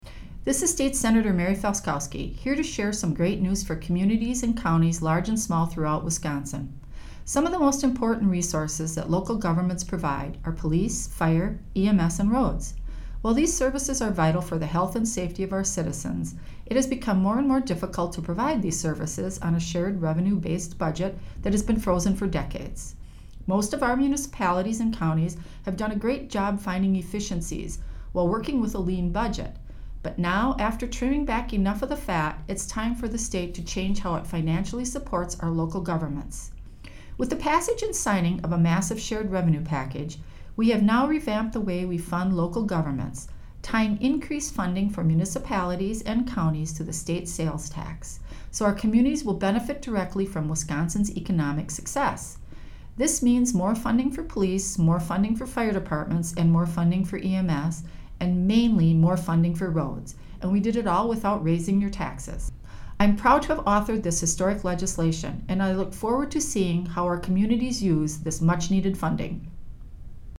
Weekly GOP radio address: Republicans ensure vital support for Wisconsin communities - WisPolitics